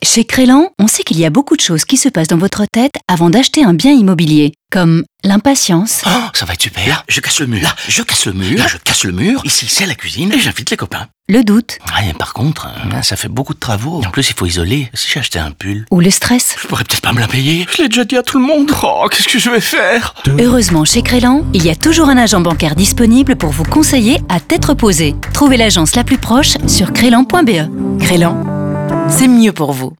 A l’instar de la radio, où une petite voix intérieure passe par toutes les émotions liées à ce moment crucial : du stress à l'euphorie, en passant par le doute.
Crelan Radio Immobilier FR.wav